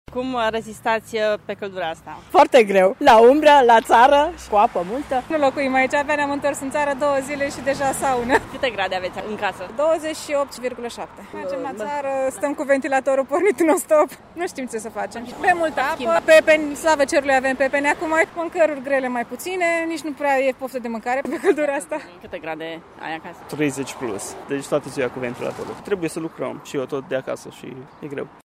În această perioadă de caniculă, târgumureșenii spun că stau mai mult în case în timpul zilei și ies pentru cumpărături, sport sau plimbări dimineața devreme sau seara târziu: